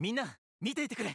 File:Marth voice sample JP.oga
Marth_voice_sample_JP.oga.mp3